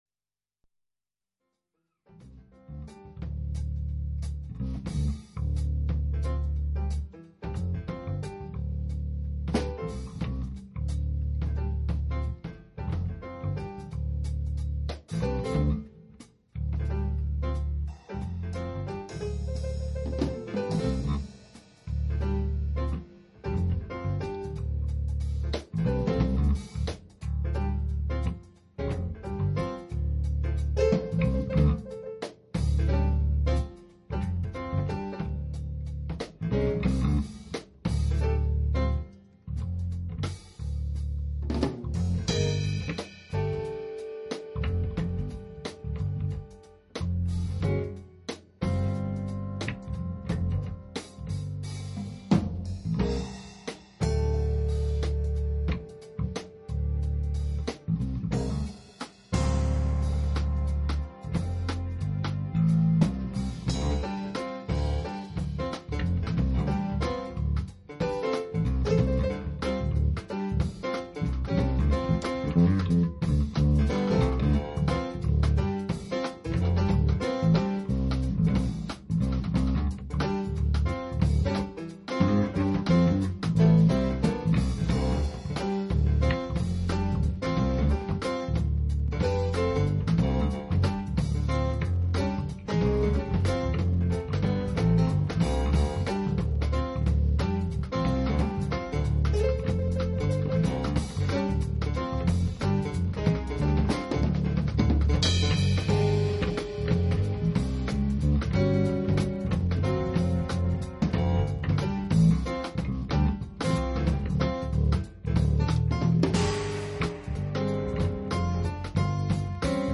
Real Time Composition. Live stereo recording on January 29th 1998
Bass
Drums
E-Piano, Piano, Synthesizer
Synthesizer We recorded direcly from the mixing desk. Also the bass was connected as a line signal. The drums had some mics, one of those unfortunately was a bit too close to the cimbal.